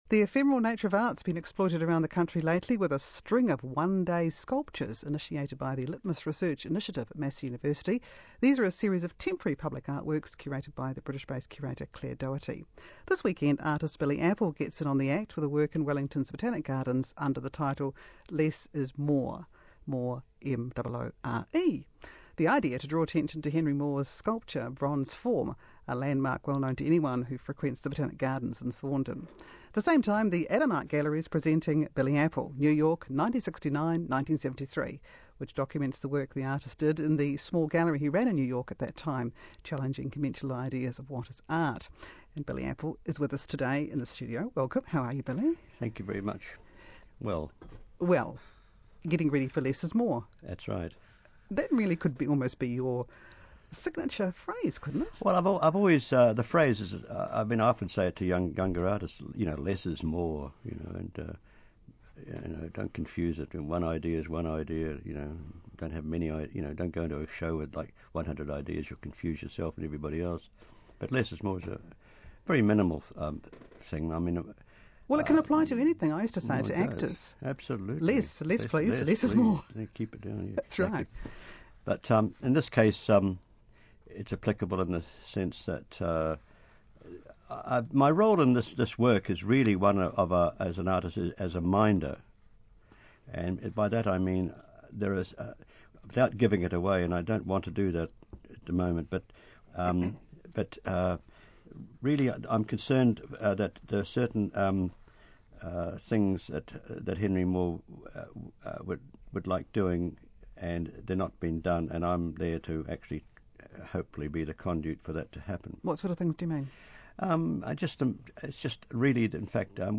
Billy Apple interviewed